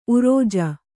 ♪ urōja